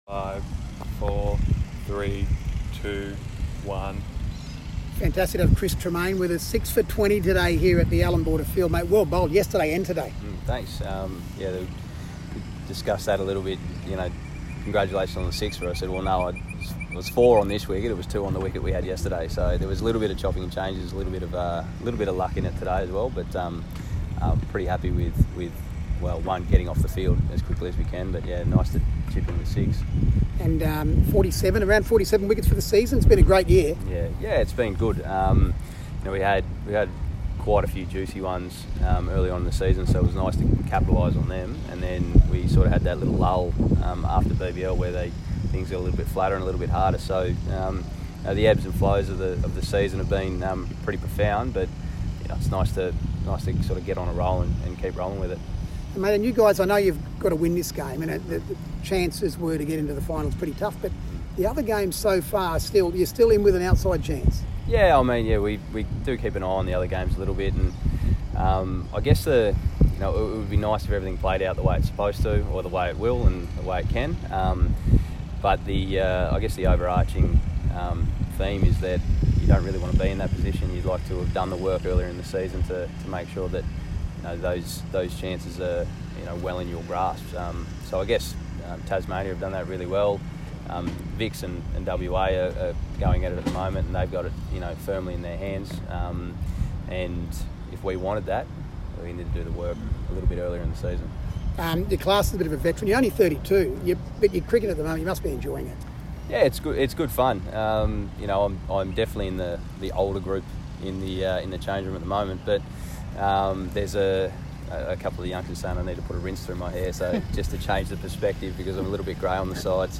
Chris Tremain New South Wales following his return of 6-20 Post-match interview (CA)